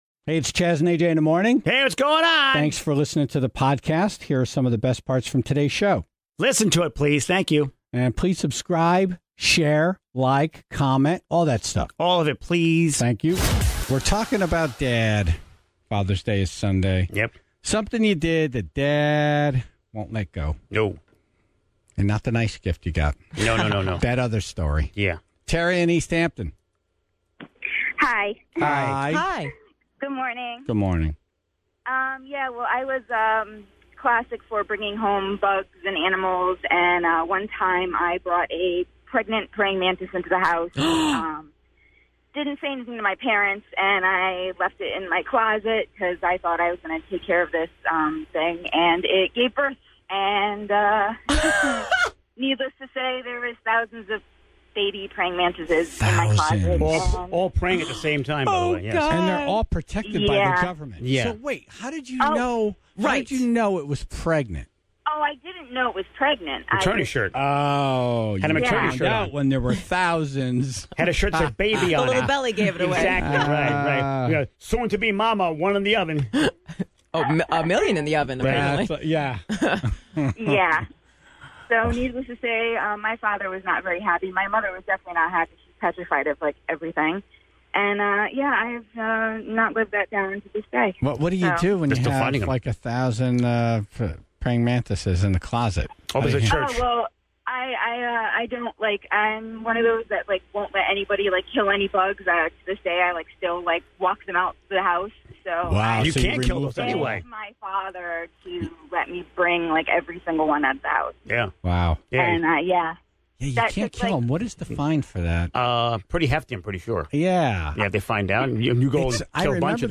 Friday, June 14: Comedian Andrew Santino In Studio And The Tribe Member Who Brought Home A Pregnant Praying Mantis
Facebook Twitter Headliner Embed Embed Code See more options Tribe member calls in about bringing a pregnant praying mantis home